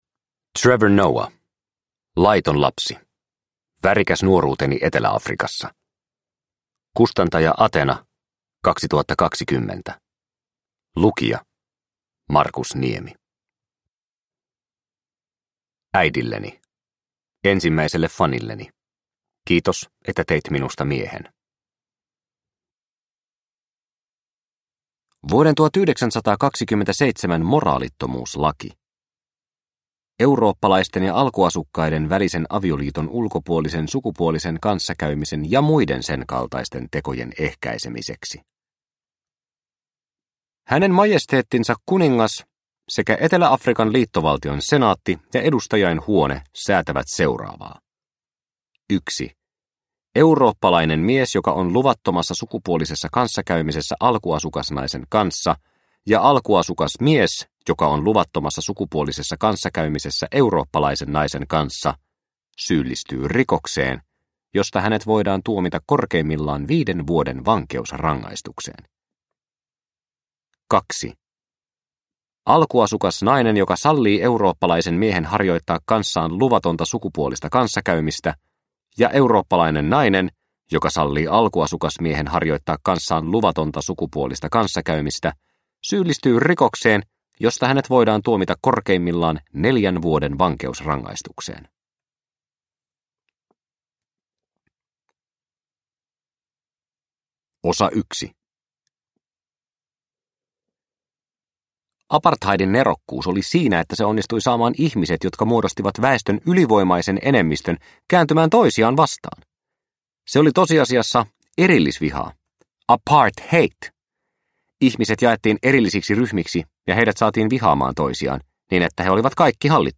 Laiton lapsi – Ljudbok – Laddas ner